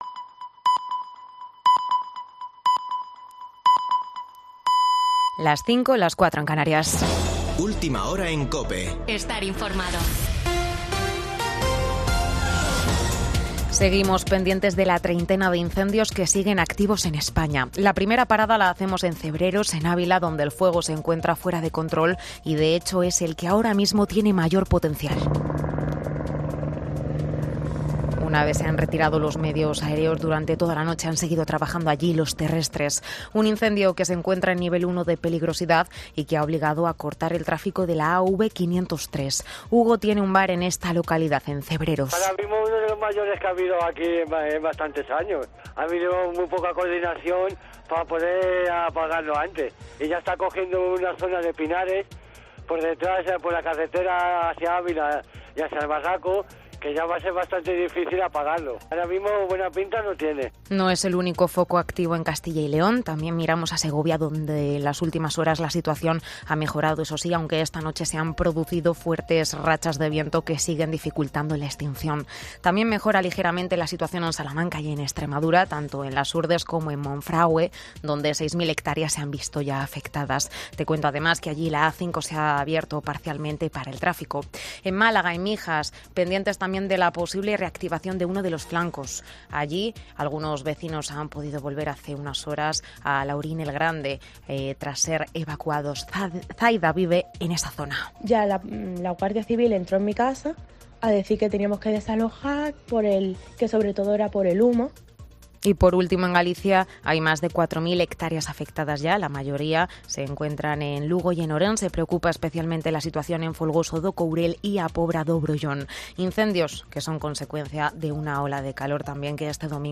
Boletín de noticias de COPE del 17 de julio de 2022 a las 05:00 horas